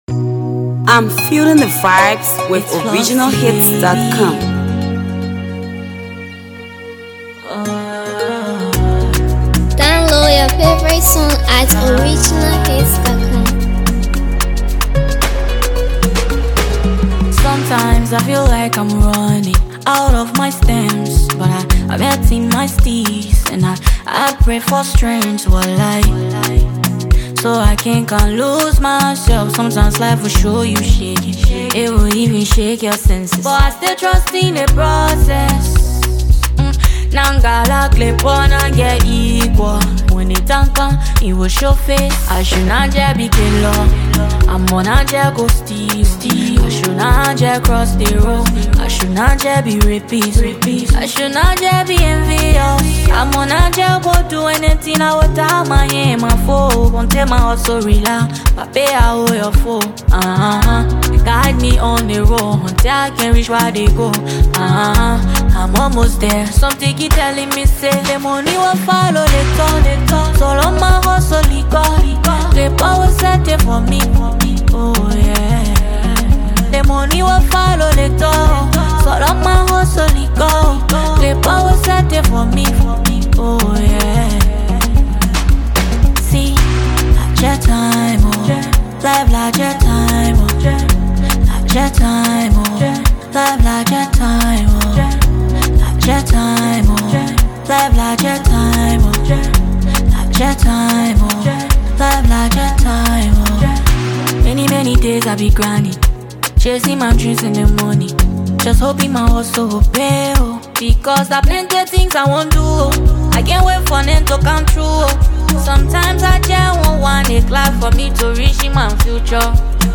songstress
banger
crooner